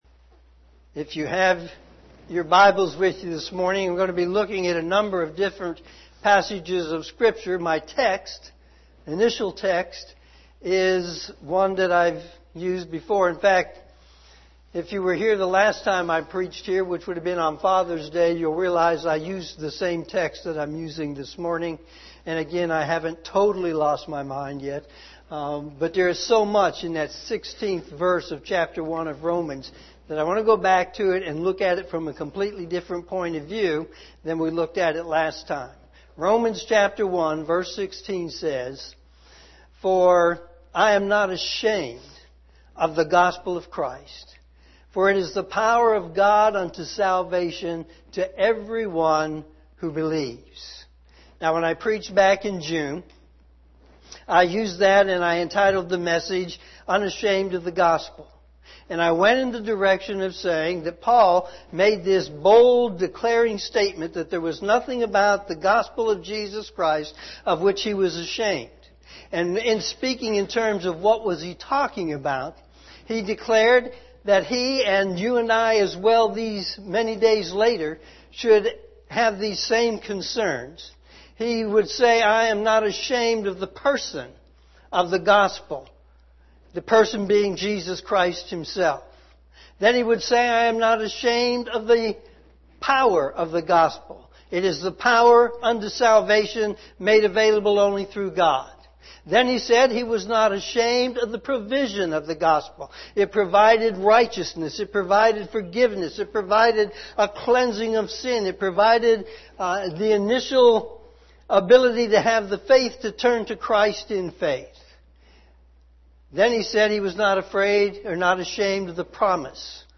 sermon8-18-19am.mp3